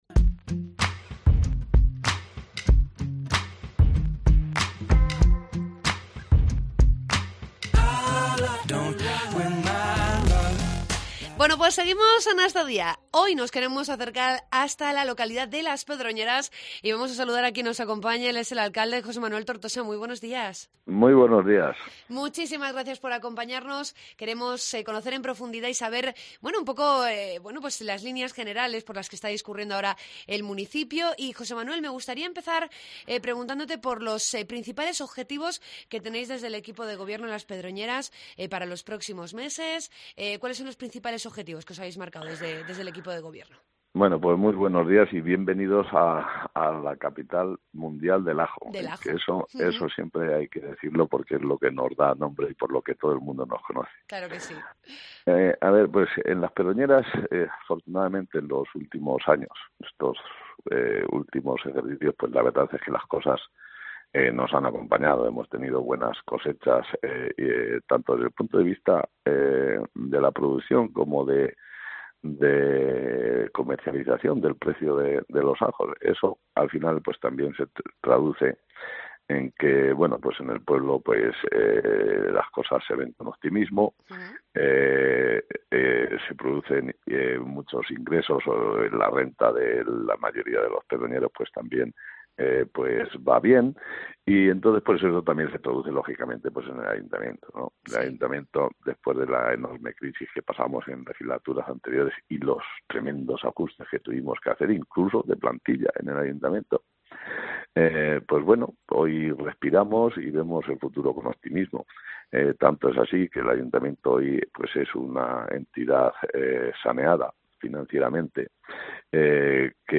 AUDIO: Entrevistamos al alcalde de Las Pedroñeras, José Manuel Tortosa.